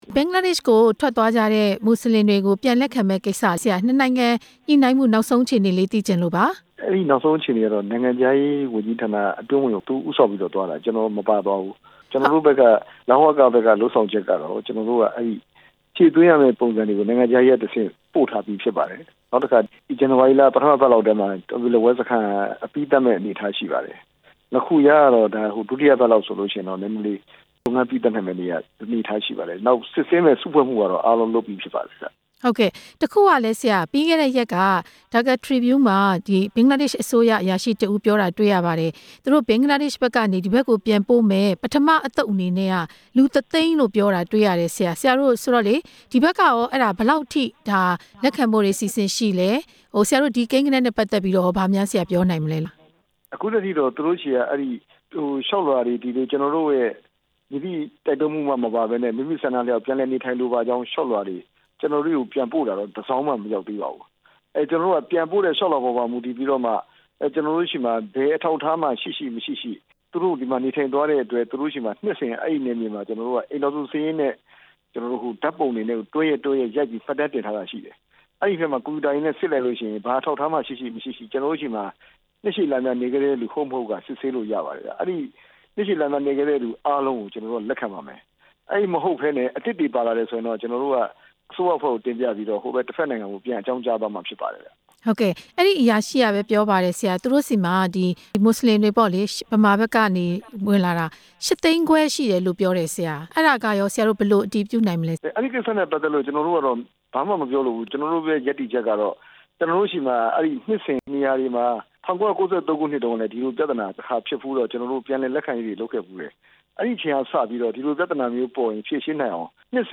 ဒုက္ခသည် ပထမအသုတ် ပြန်လက်ခံရေး မေးမြန်းချက်